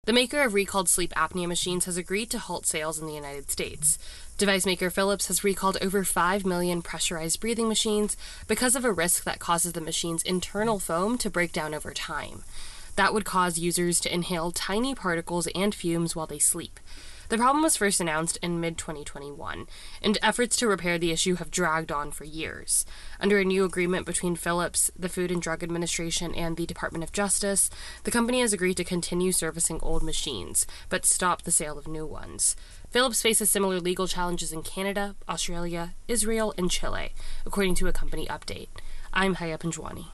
The maker of recalled sleep apnea machines has agreed to halt sales in the United States. AP correspondent